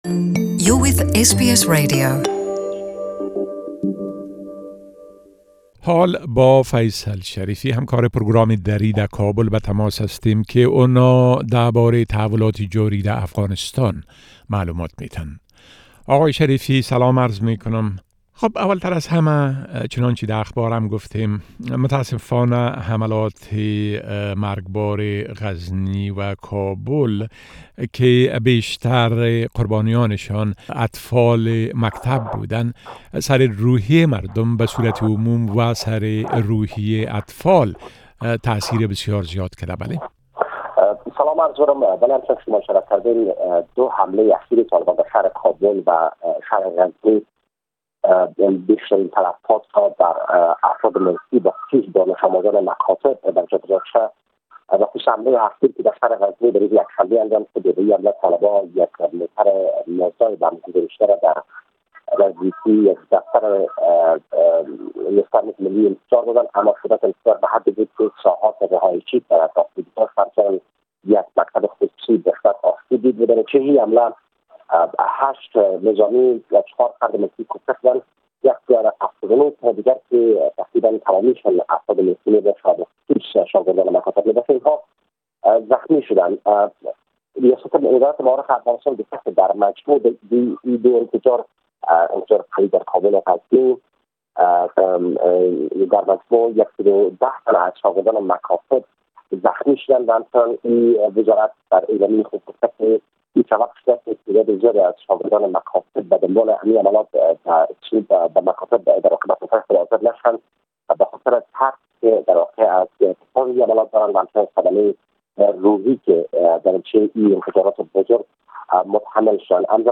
Our correspondent's report from Kabul can be heard here.